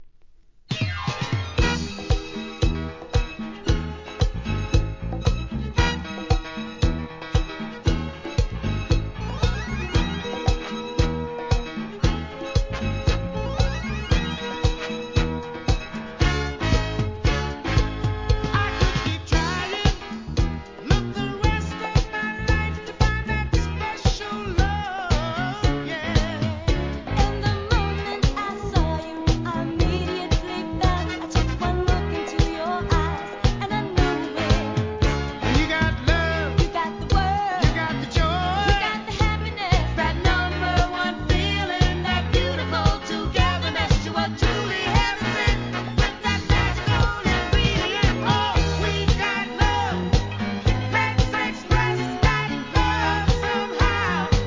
¥ 550 税込 関連カテゴリ SOUL/FUNK/etc...